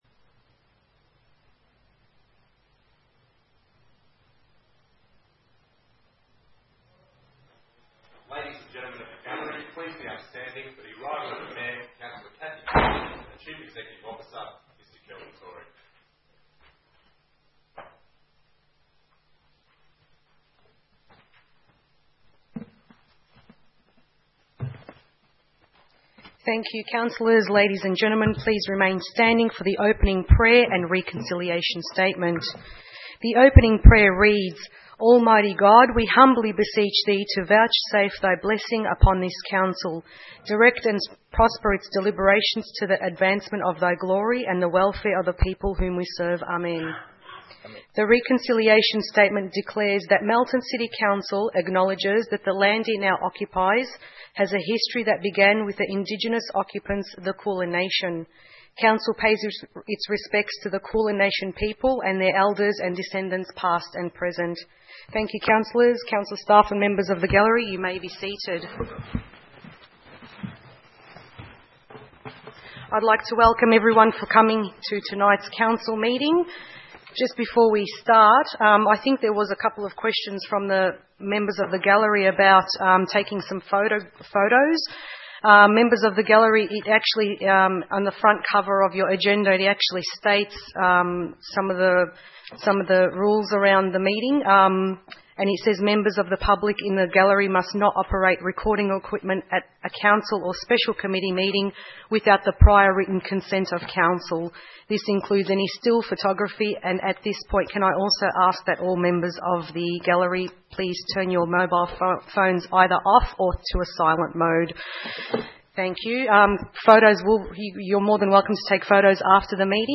8 February 2016 - Ordinary Council Meeting